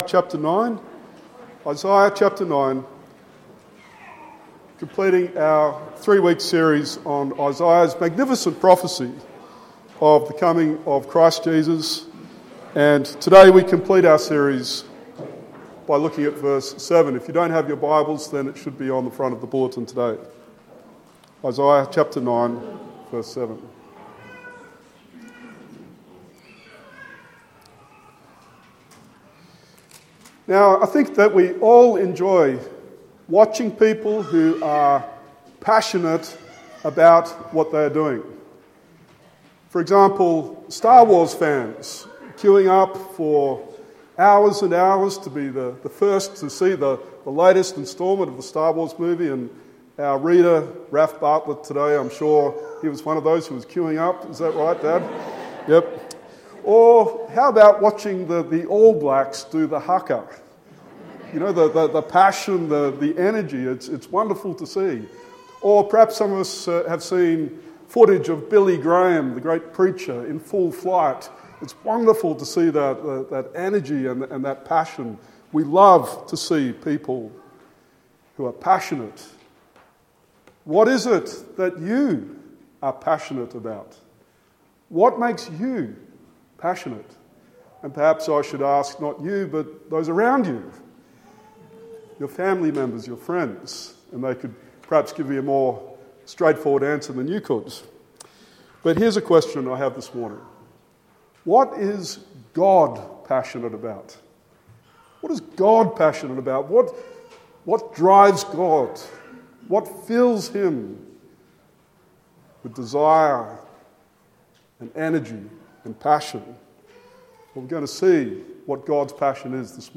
Isaiah 9:1-7 Sermon